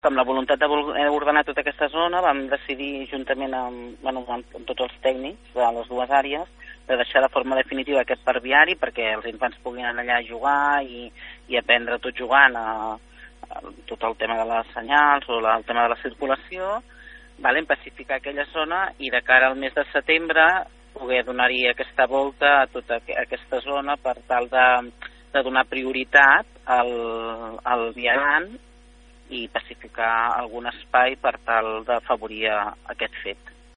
De tot plegat en parla Susanna Pla, regidora de Governació de l’Ajuntament de Palafolls.